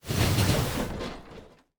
train-tie-1.ogg